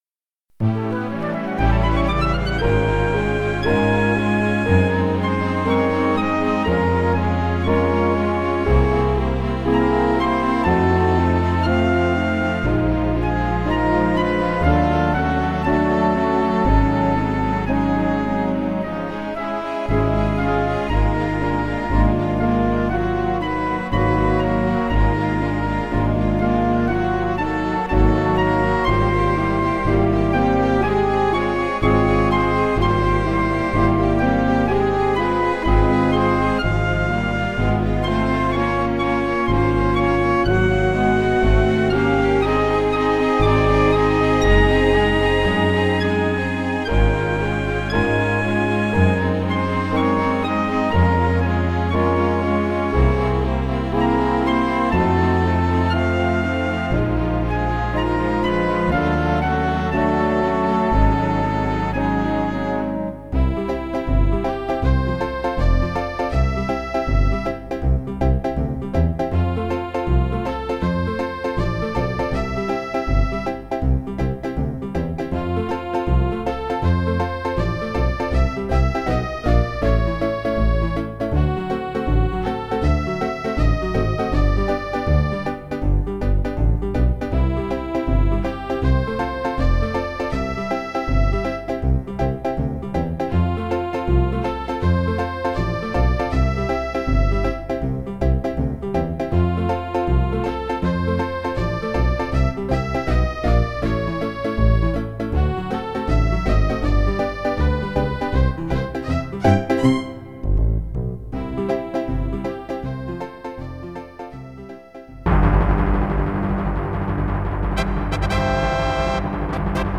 Roland MT-32 and Sound Canvas Enhanced version.